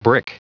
Prononciation du mot brick en anglais (fichier audio)
Prononciation du mot : brick